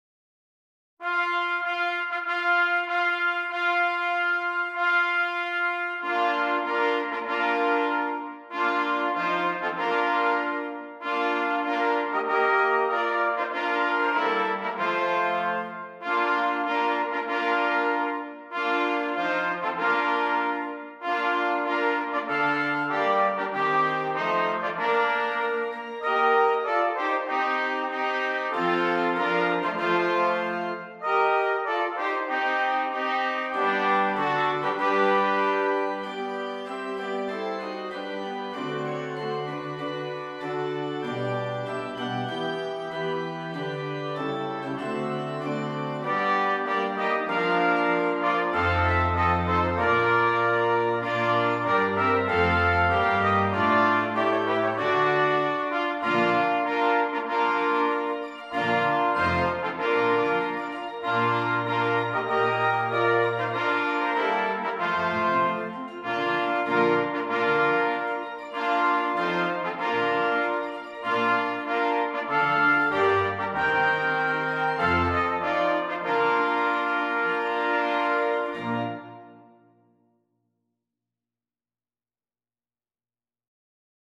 Brass Quartet and Organ